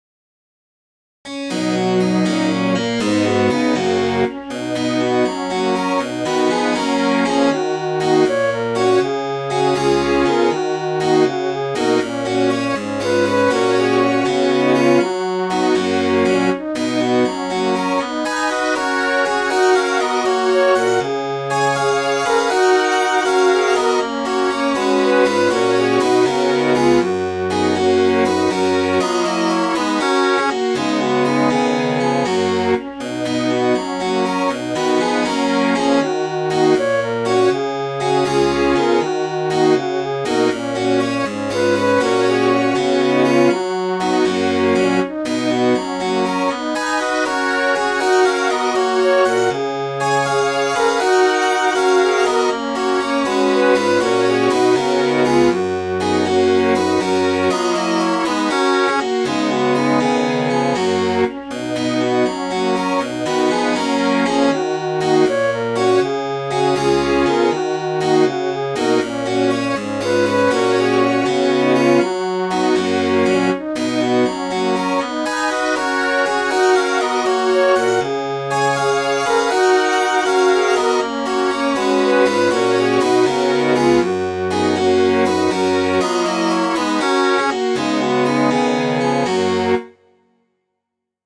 Midi File, Lyrics and Information to As I Walked Through the Meadow